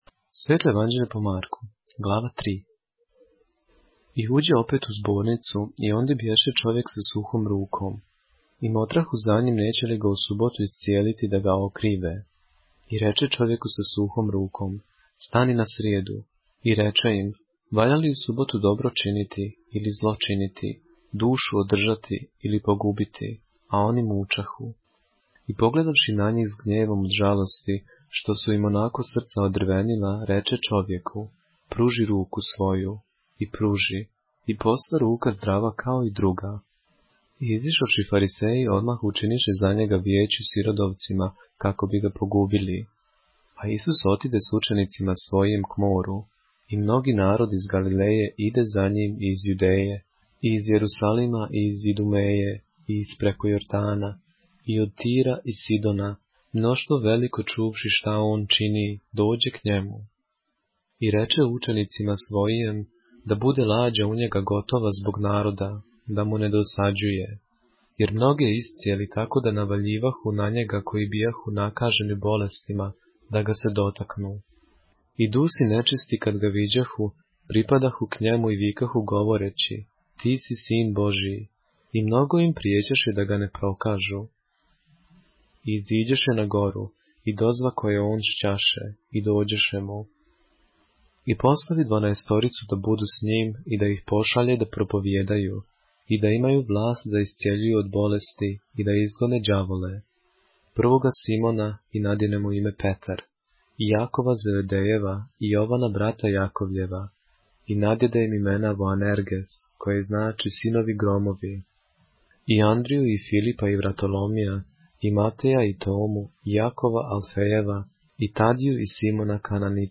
поглавље српске Библије - са аудио нарације - Mark, chapter 3 of the Holy Bible in the Serbian language